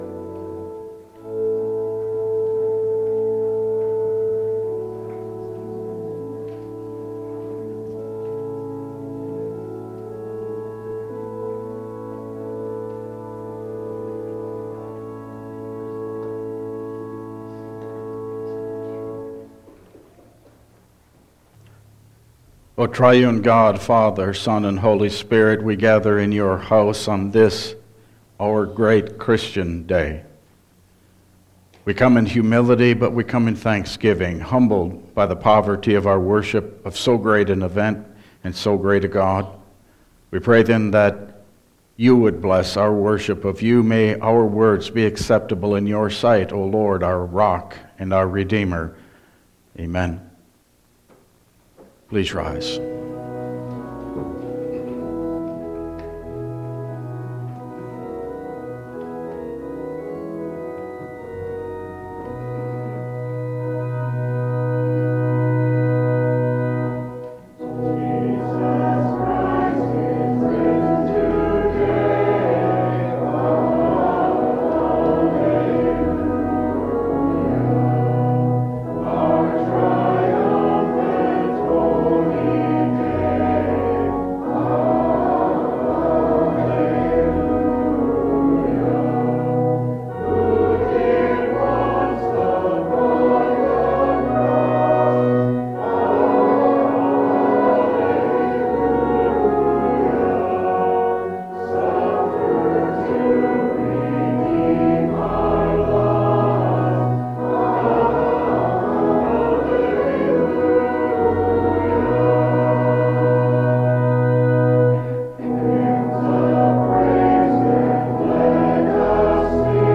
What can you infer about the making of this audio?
Service Type: Easter Sunday